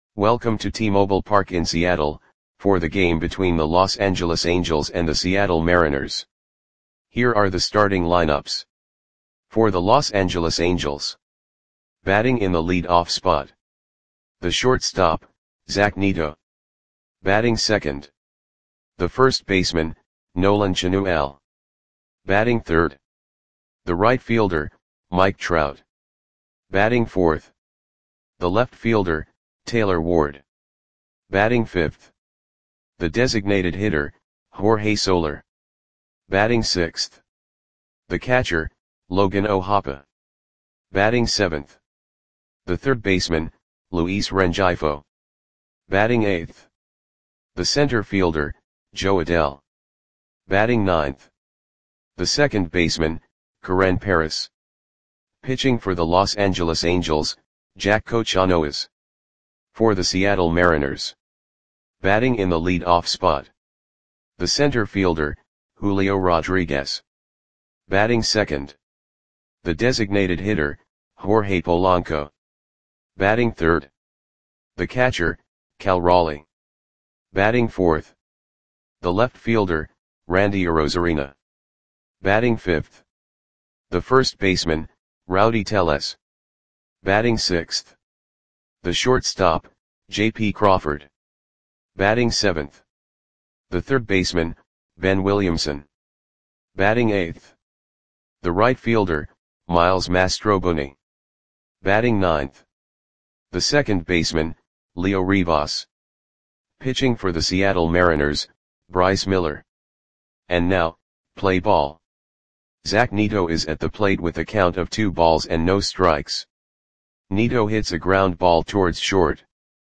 Audio Play-by-Play for Seattle Mariners on April 29, 2025
Click the button below to listen to the audio play-by-play.